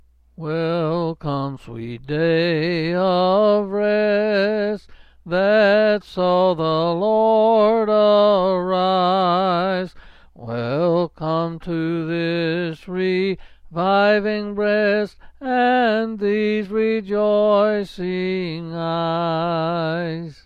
Quill Pin Selected Hymn
S. M.